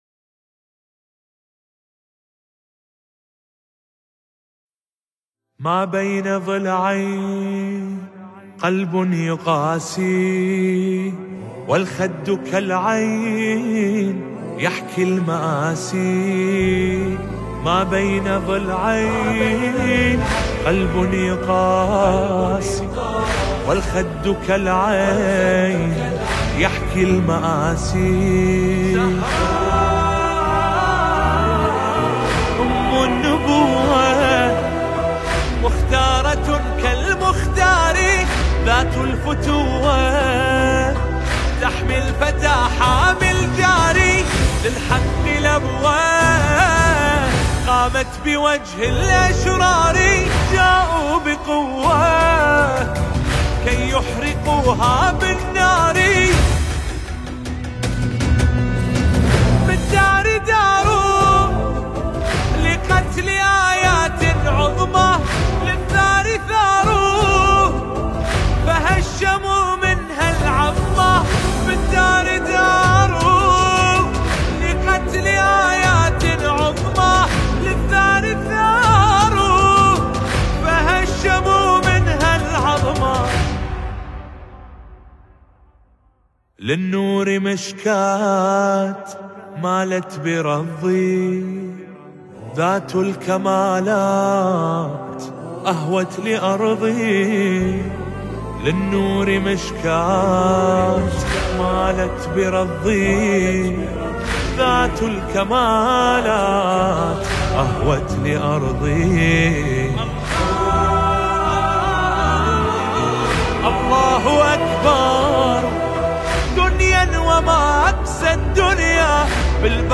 أداء
الهندسة الصوتية والتوزيع